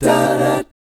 1-CMI7    -R.wav